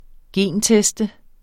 Udtale [ ˈgεn- ]